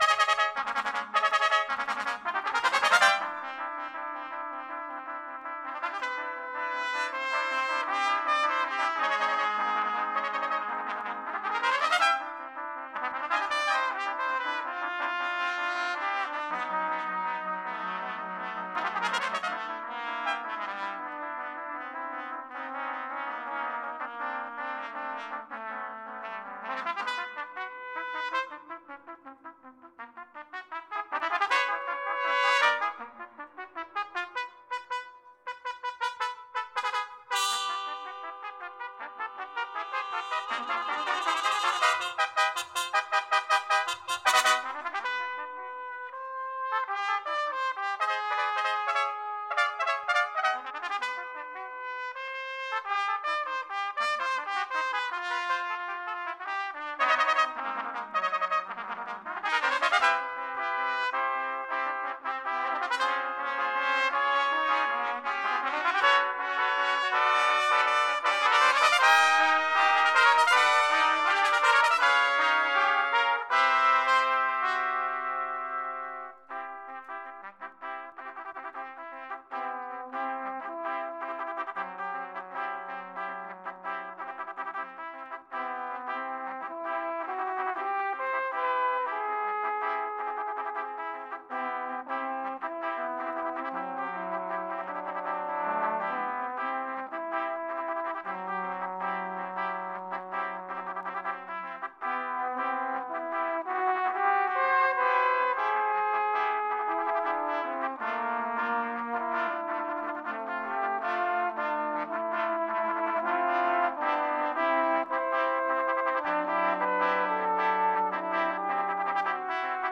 Three of the parts double on flugelhorns.)